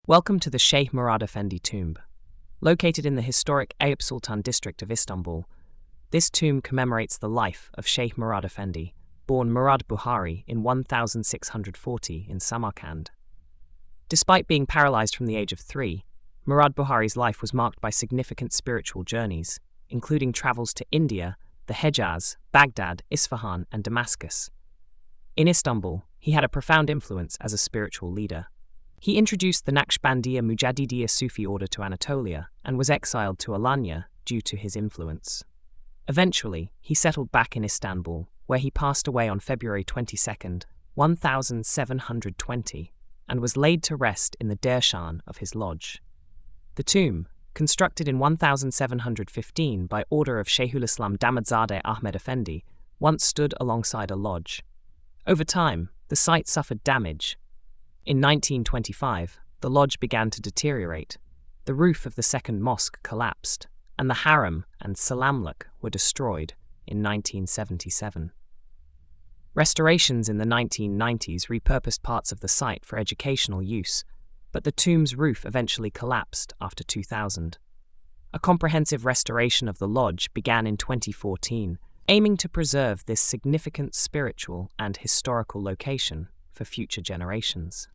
Audio Narration: